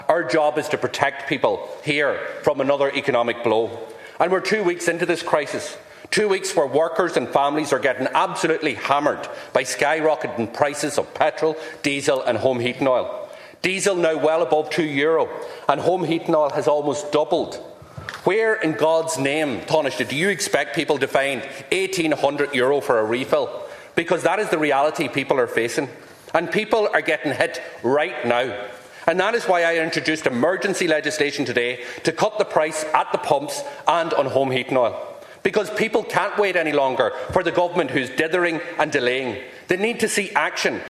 It was moved a short time ago by the party’s Finance Spokesperson, Donegal TD Pearse Doherty……………..